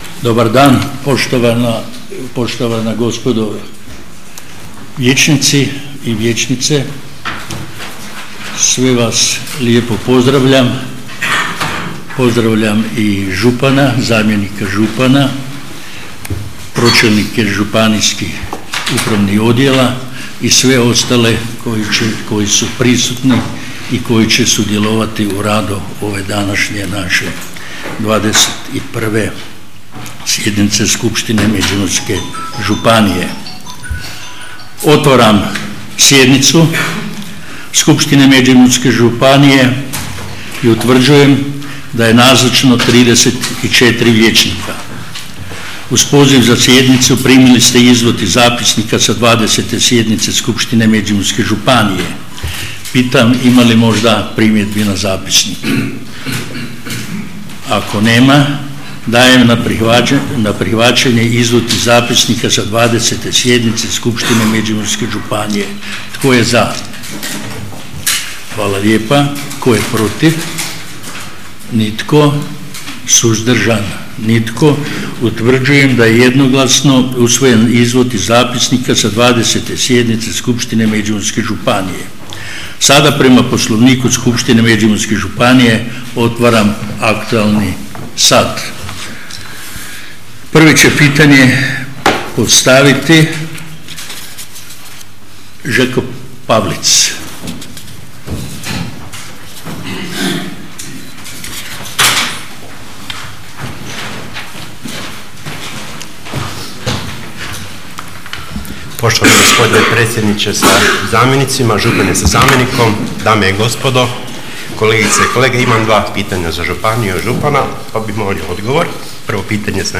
21. sjednica Skupštine Međimurske županije